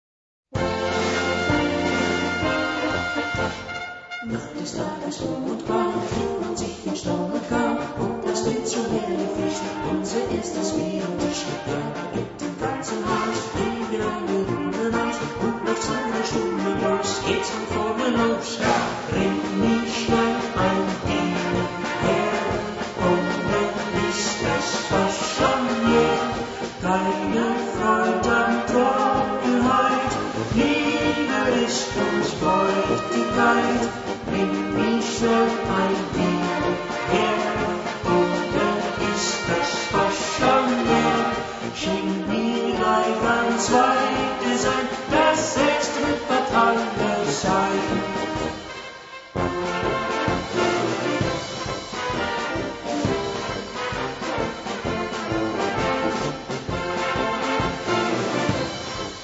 Gattung: Polka mit Text
Besetzung: Blasorchester